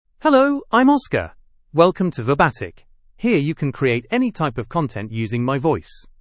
MaleEnglish (United Kingdom)
Oscar is a male AI voice for English (United Kingdom).
Voice sample
Oscar delivers clear pronunciation with authentic United Kingdom English intonation, making your content sound professionally produced.